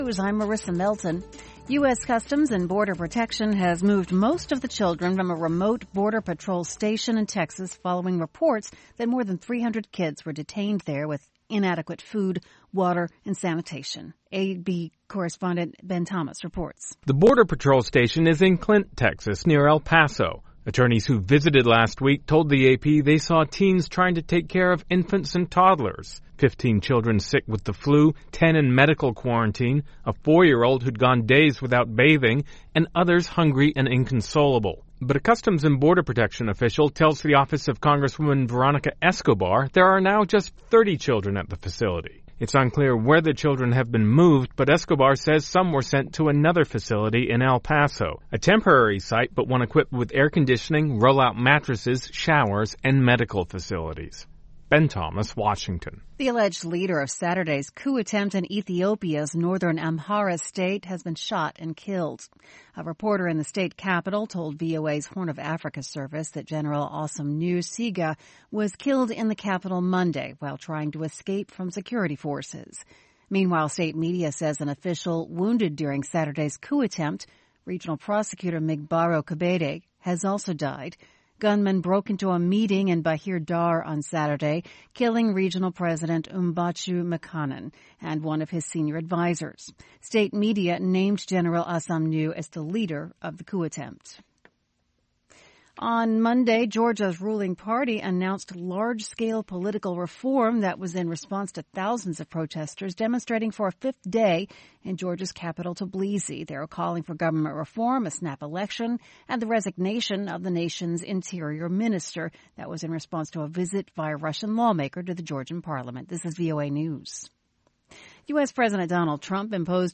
Around the clock, the Voice of America keeps you in touch with the latest news. We bring you reports from our correspondents and interviews with newsmakers from across the world.